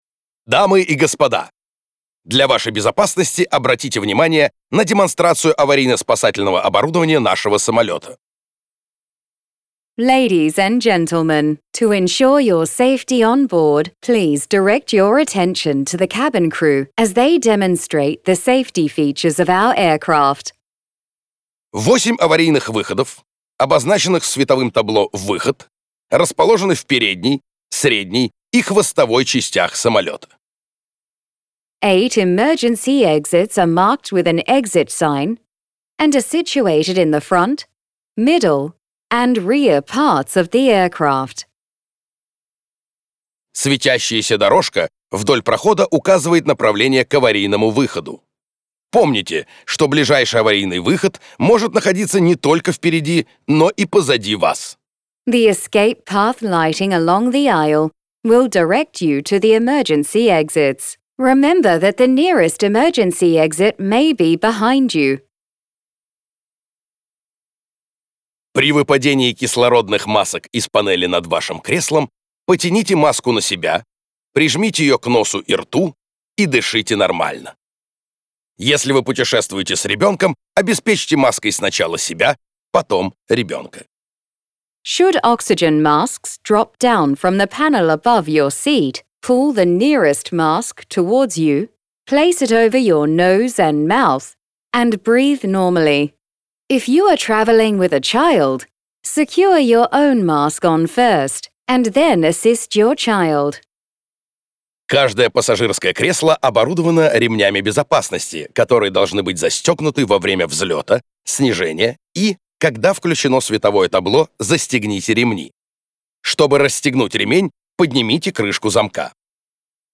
Авиакомпания «Победа» убрала из самолетов голос Гарика Харламова, которым были озвучены сообщения автоинформатора.
Вот для сравнения старая запись с Харламовым: